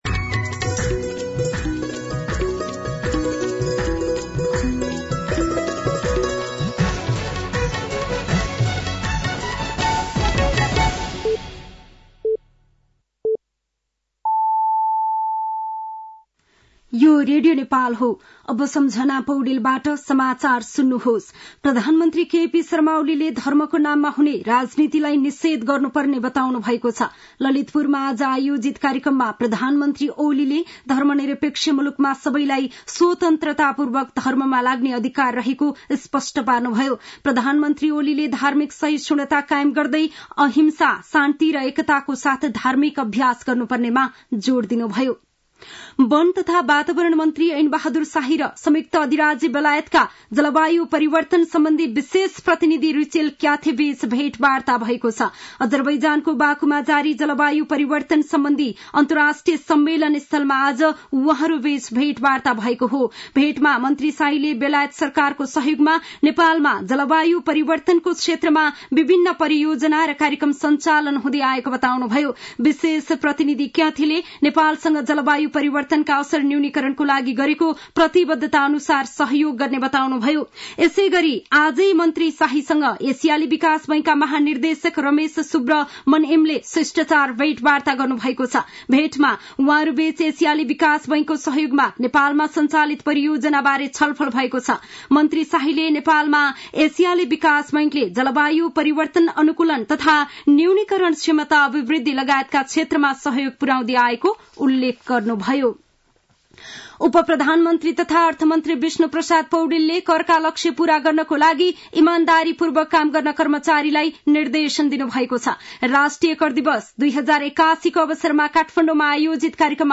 साँझ ५ बजेको नेपाली समाचार : २ मंसिर , २०८१
5-PM-Nepali-News-8-1.mp3